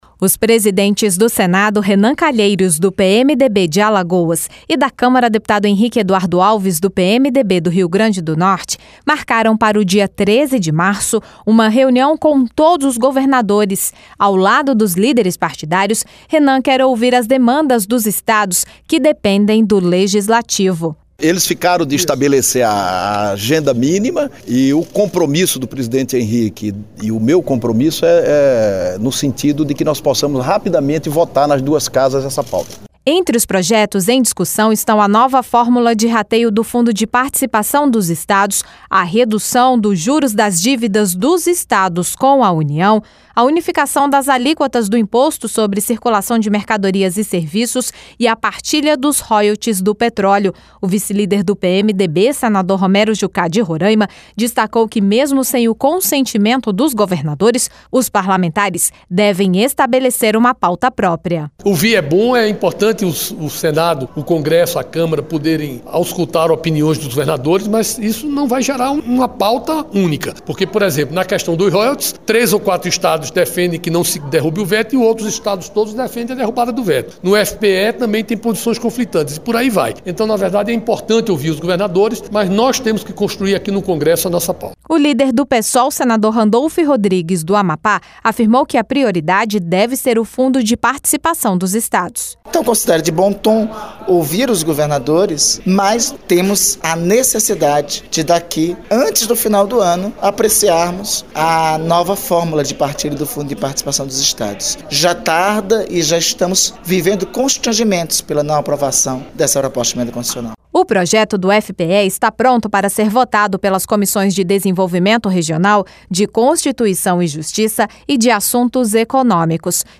LOC: GOVERNADORES VÊM AO CONGRESSO PARA DISCUTIR PRIORIDADES EM REUNIÃO MARCADA PARA MARÇO.